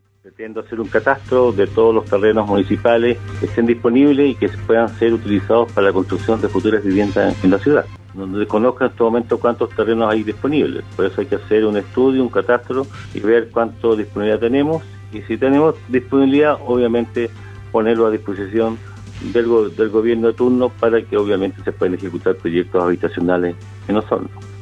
En conversación con Radio Sago, el alcalde electo de la comuna de Osorno, Emeterio Carrillo, se refirió al importante déficit habitacional existente en la ciudad y al exponencial crecimiento de la zona urbana.